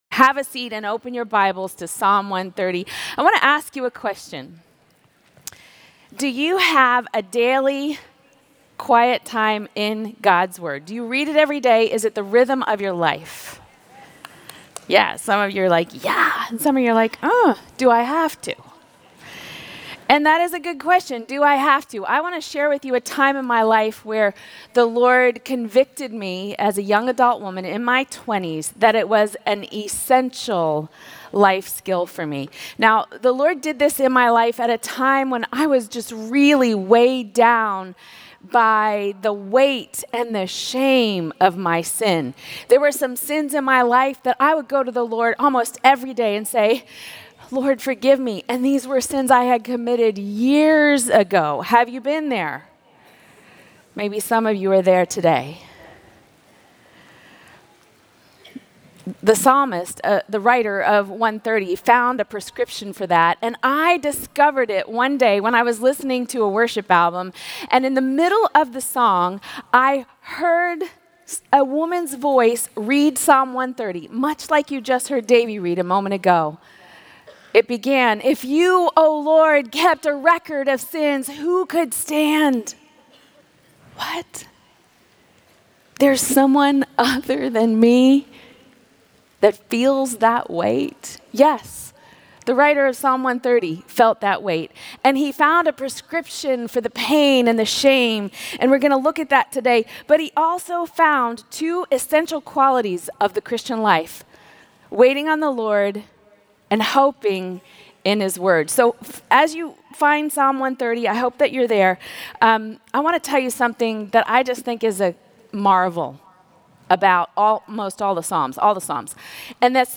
Behold the Daily Mercy of the Word | True Woman '25 | Events | Revive Our Hearts